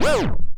BUZZ DOWN.wav